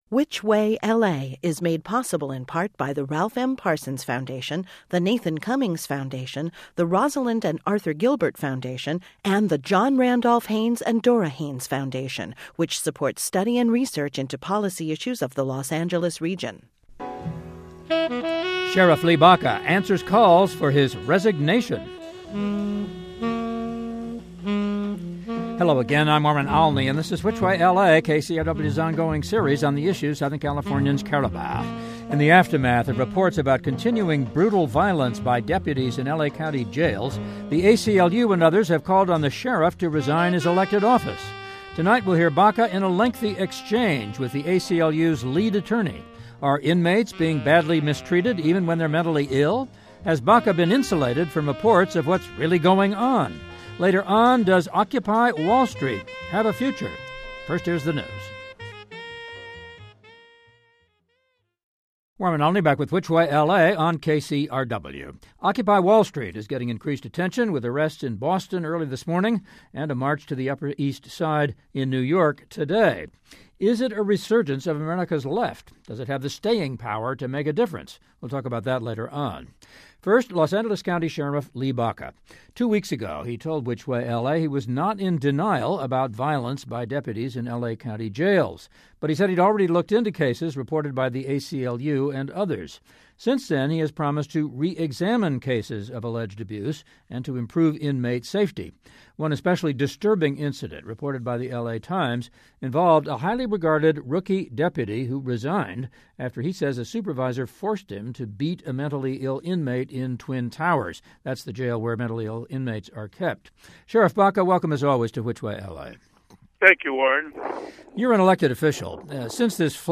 We talk with Baca, an ACLU leader and others.